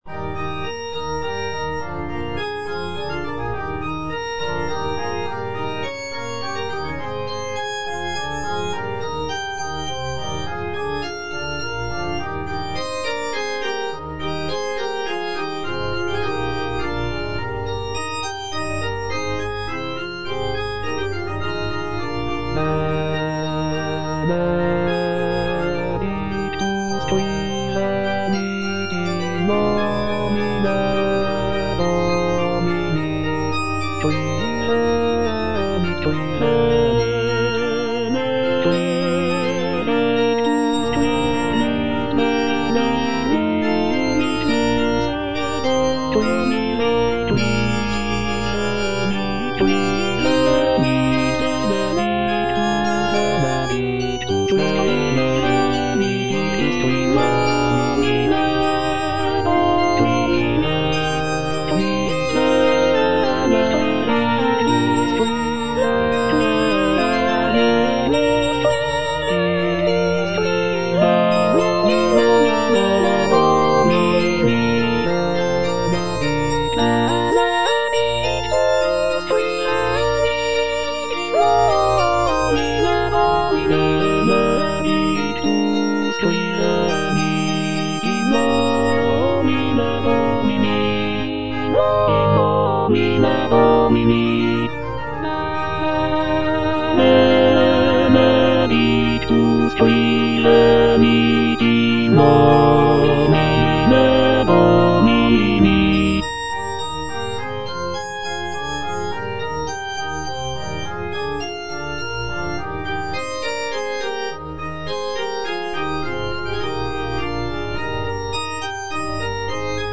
M. HAYDN - REQUIEM IN C (MISSA PRO DEFUNCTO ARCHIEPISCOPO SIGISMUNDO) MH155 Benedictus - Tenor (Emphasised voice and other voices) Ads stop: auto-stop
It was written as a requiem mass in memory of Archbishop Sigismund von Schrattenbach. The work is characterized by its somber and mournful tone, reflecting the solemnity of a funeral mass.